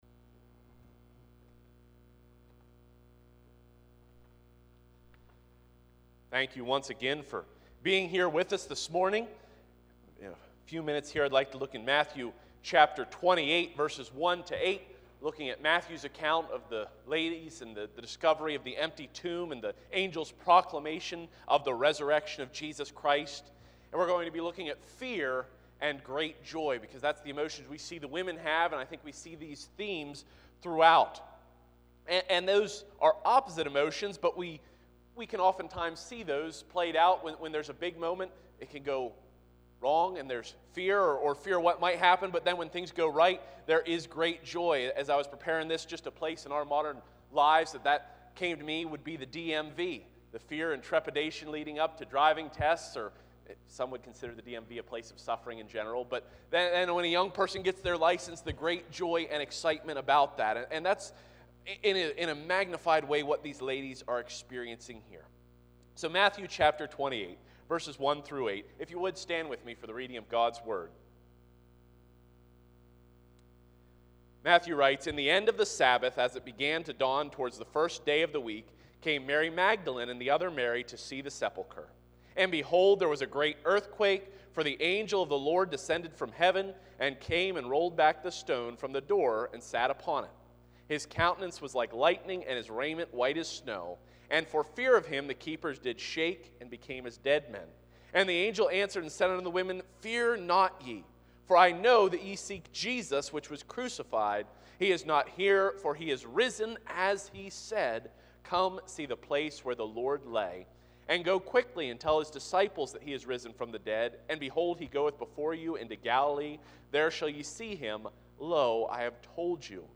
Matthew 28:1-8 Service Type: Special Service I. The Solemn Approach v. 1-2 II.